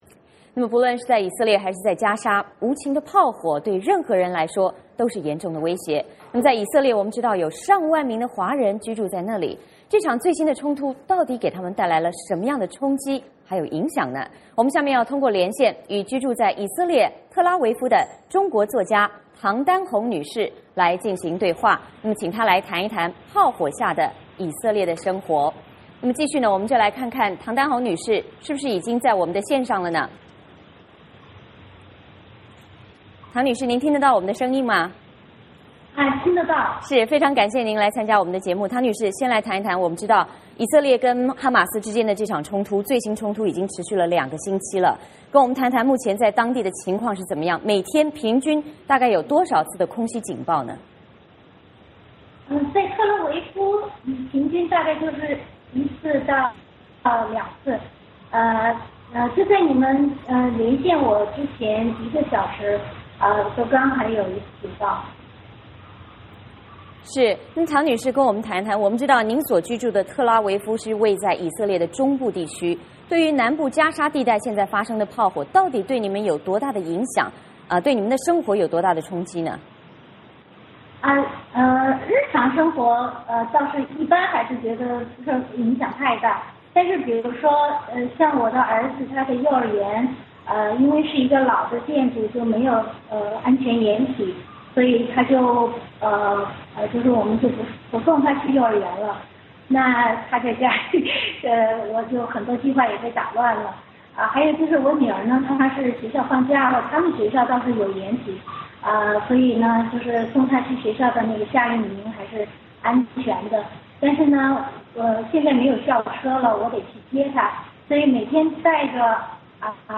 不论是在以色列还是在加沙，无情的炮火对任何人都是严重威胁，在以色列有上万名华人居住在那里，这场最新冲突给他们带来什么样的冲击和影响?我们通过连线